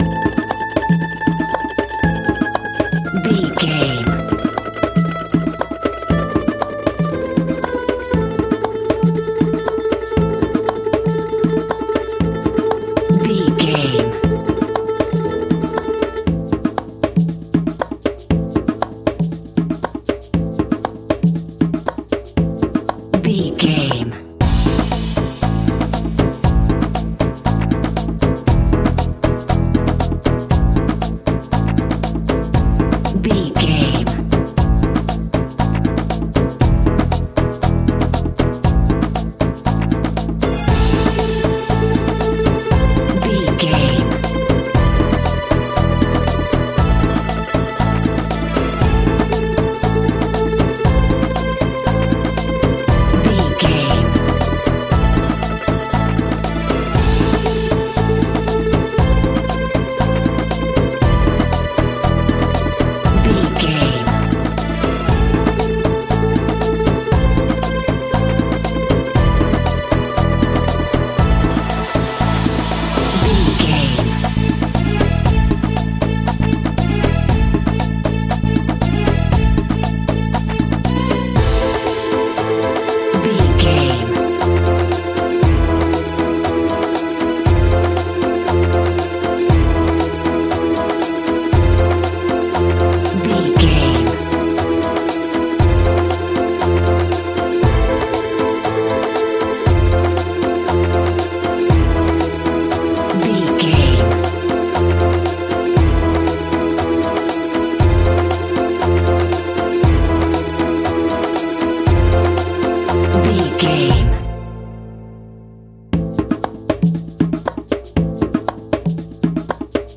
Aeolian/Minor
Indian
World Music
percussion